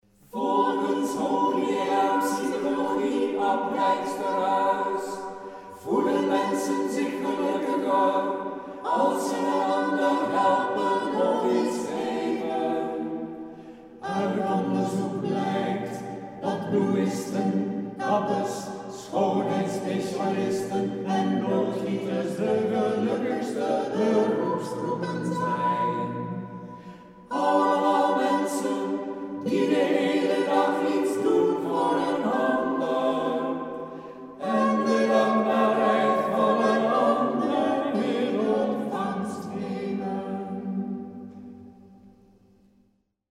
In kwartetjes gezongen op de wijze van vierstemmige Engelse ‘chants’: